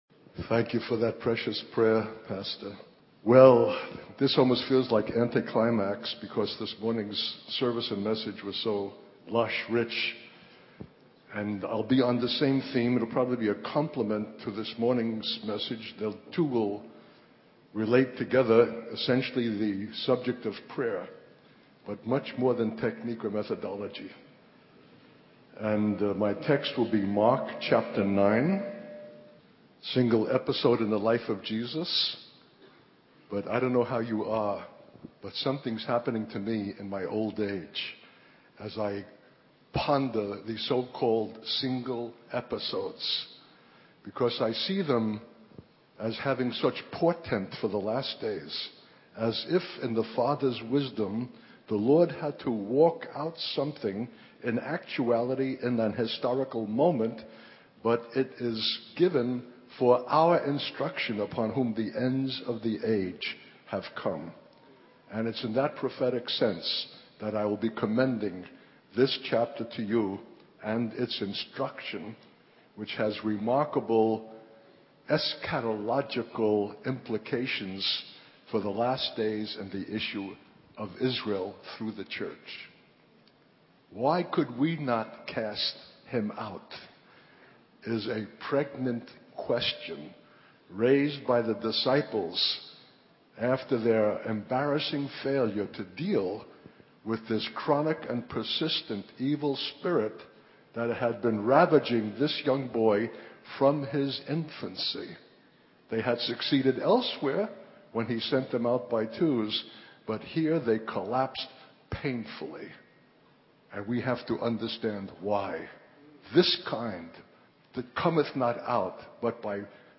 In this sermon, the speaker emphasizes the importance of retaining the vision of the transfigured Lord in the face of demonic opposition and challenges. He encourages believers to persist in prayer and devotion, even when it may feel inadequate, as God imparts something to them in those moments. The speaker also highlights the need to break free from a utilitarian mentality that expects a measured return for one's investment, as the kingdom of God operates on a lavish and extravagant principle.